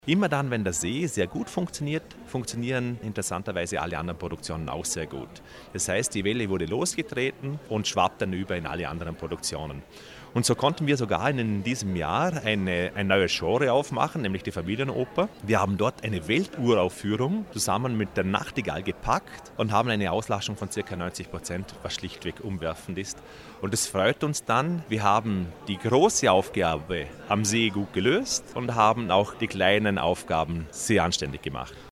Pressekonferenz Endspurt O-Ton